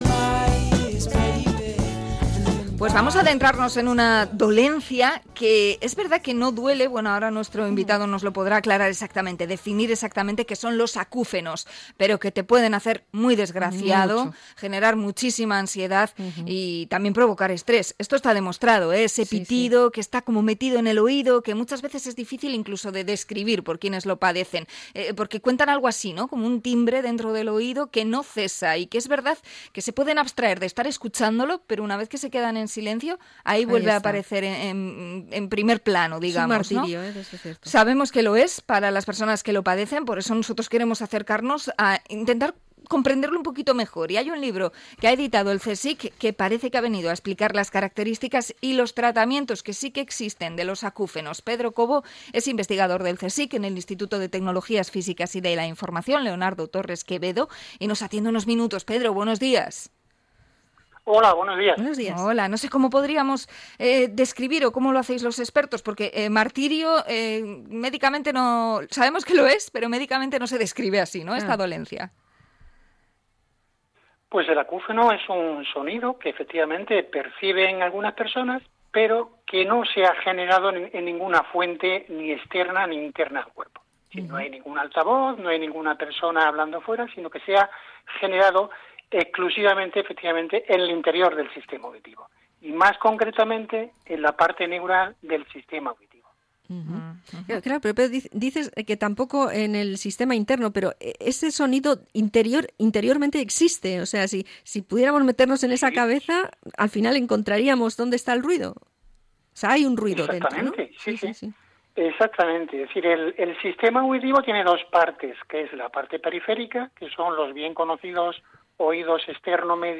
Entrevista a investigador del CSIC sobre el acúfeno